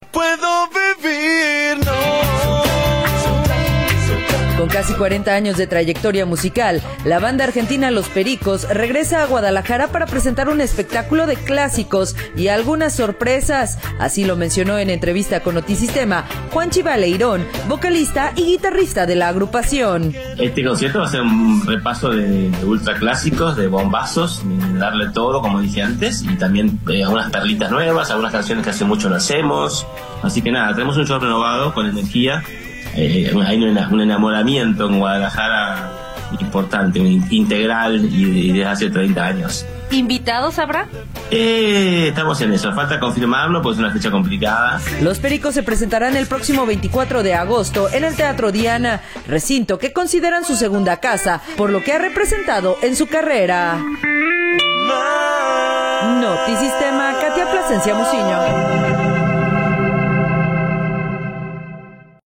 Con casi 40 años de trayectoria musical, la banda argentina Los Pericos regresa a Guadalajara para presentar un espectáculo de clásicos y algunas sorpresas, así lo mencionó en entrevista con Notisistema, Juanchi Baleirón, vocalista y guitarrista de la agrupación.